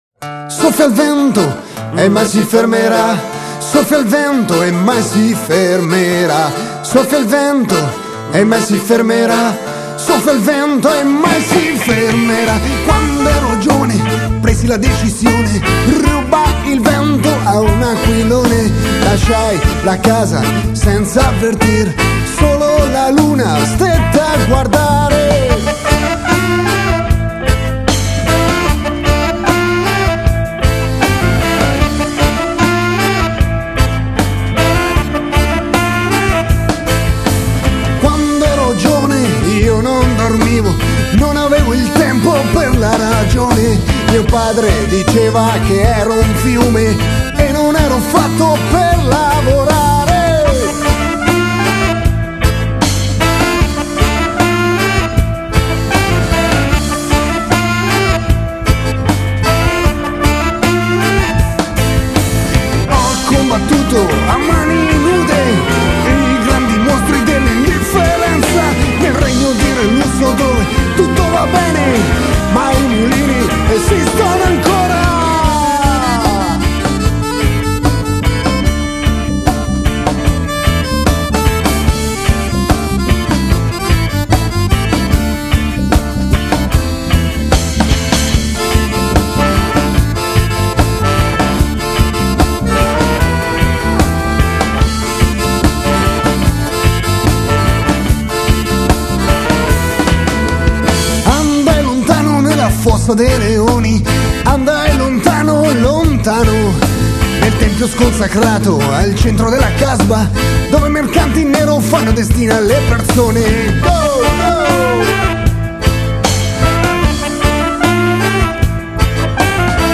Il disco è stato registrato nello studio di registrazione